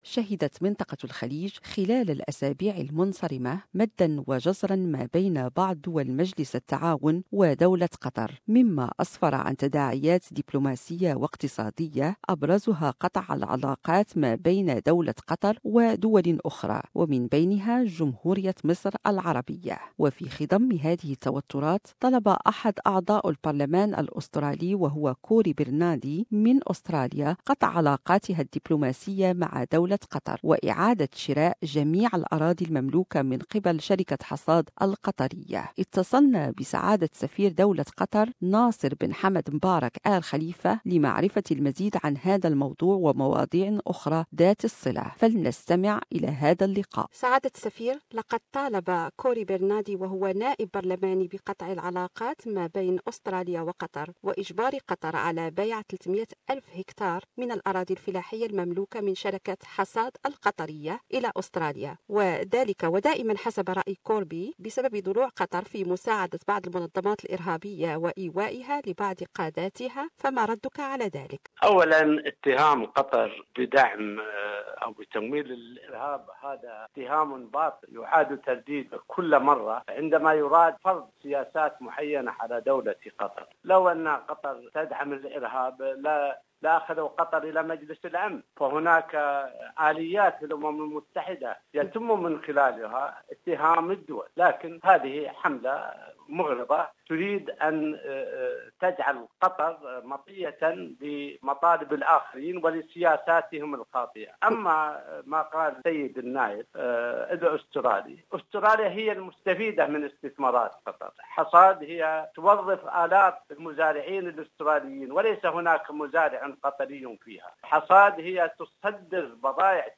Interview with Ambassador Nasser bin Hamad Mubarak Al-Khalifa.